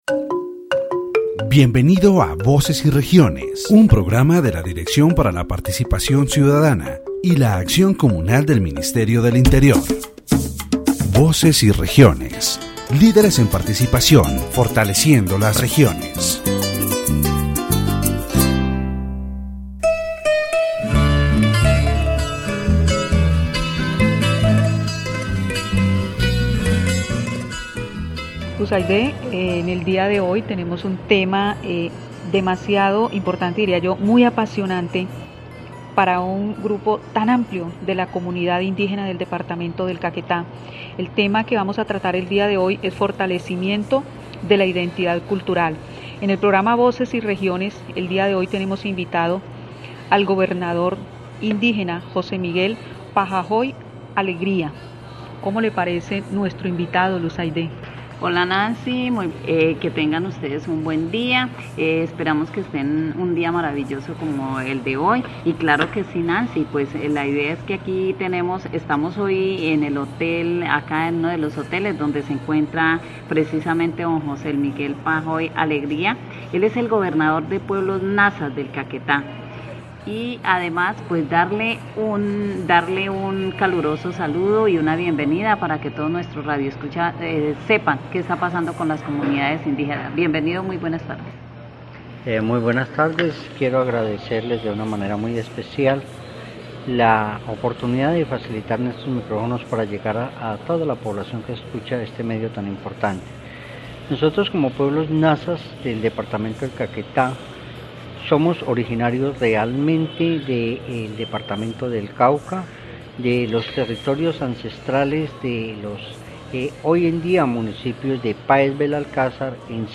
The radio program "Voices and Regions" of the Directorate for Citizen Participation and Communal Action of the Ministry of the Interior focuses on strengthening the cultural identity of the NASA indigenous community in the department of Caquetá. In this episode, José Miguel Pajoy Alegría, indigenous governor of the NASA people, discusses the history and culture of his community, including the conservation of the NASA Yuwe language and traditional rituals. Topics are addressed such as the relationship between indigenous authorities and government institutions, challenges in project and resource management, and the importance of cultural resistance.